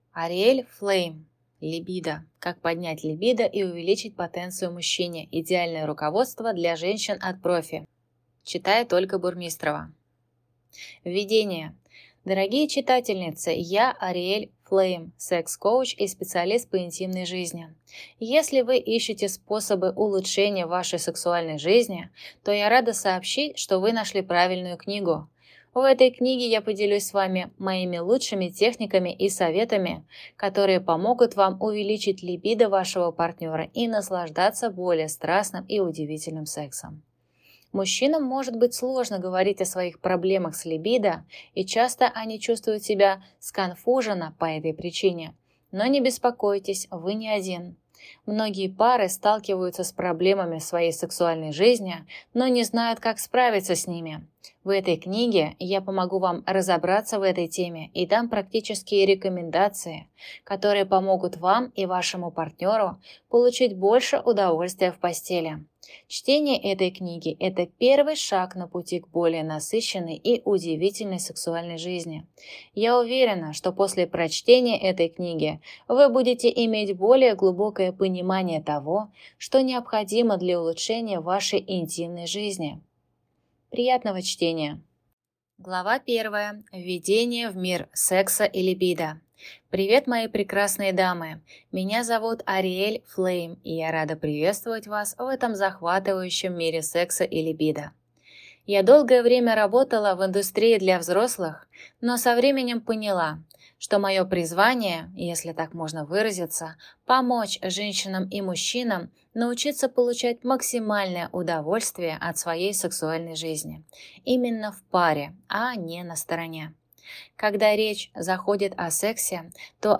Аудиокнига Либидо. Как поднять либидо и увеличить потенцию мужчине. Идеальное руководство для женщин от профи | Библиотека аудиокниг